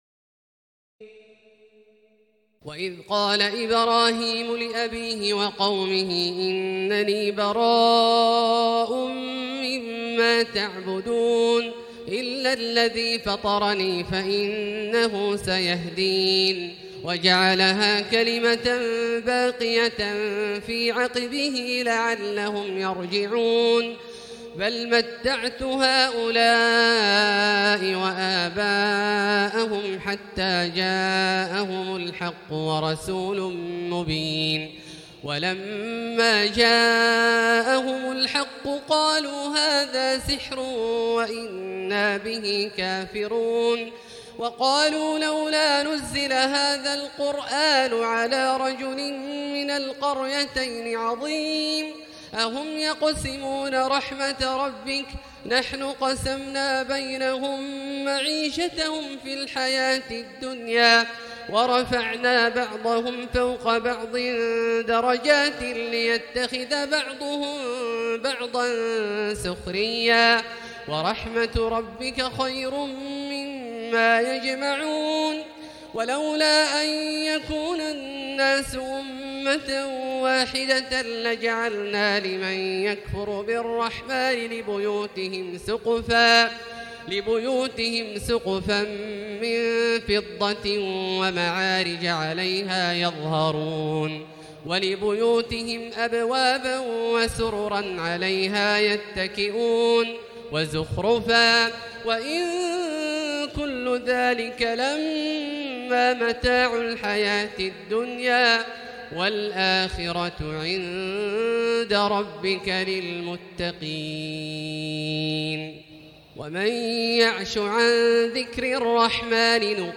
تراويح ليلة 24 رمضان 1439هـ من سور الزخرف (26-89) والدخان و الجاثية Taraweeh 24 st night Ramadan 1439H from Surah Az-Zukhruf and Ad-Dukhaan and Al-Jaathiya > تراويح الحرم المكي عام 1439 🕋 > التراويح - تلاوات الحرمين